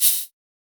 Dilla Shaker 10.wav